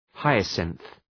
Προφορά
{‘haıəsınɵ}